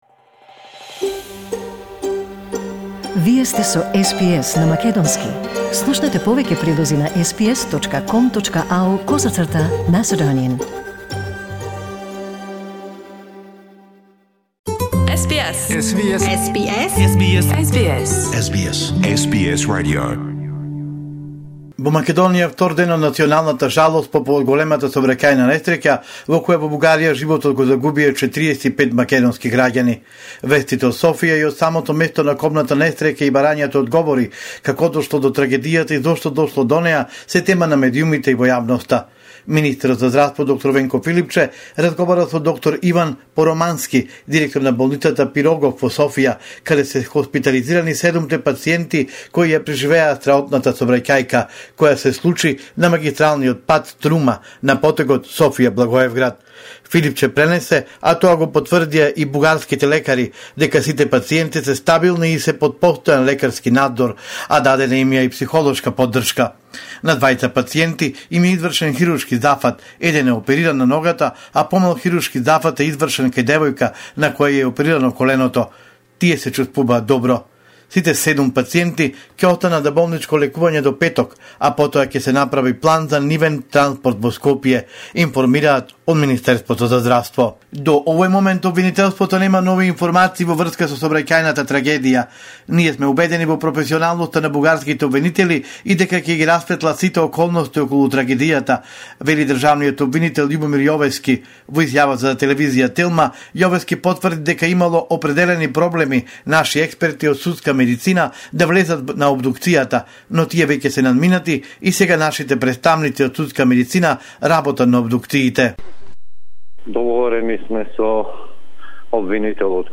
Homeland Report in Macedonian 25 November 2021